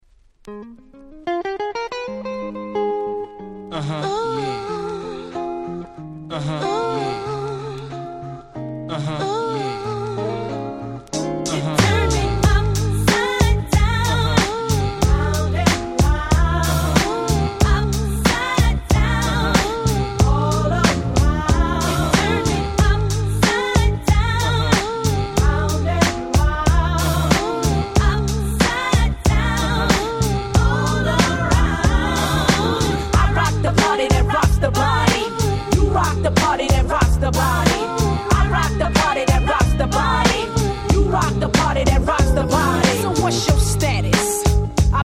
97' Big Hit Hip Hop !!
まるで明け方のフロアを温かく包み込むかの様な好Remix、もう原曲がいったい何だったのか？